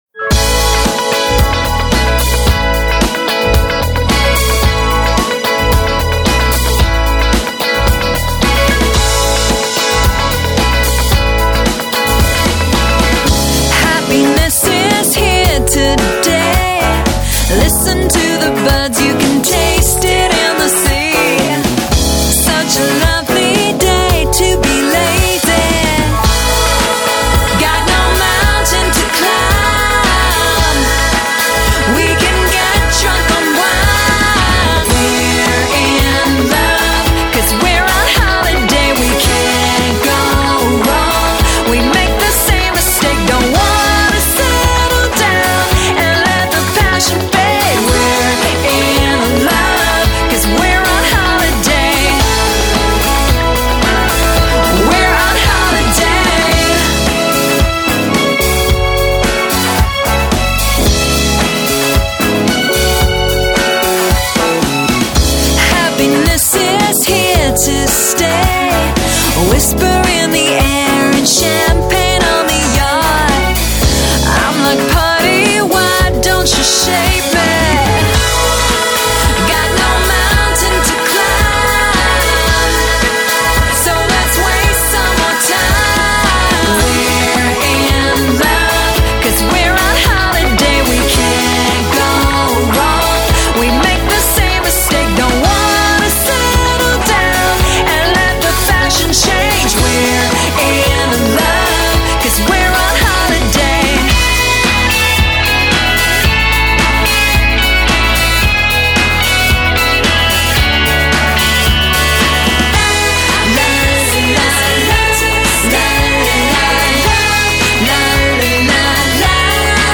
(retro pop)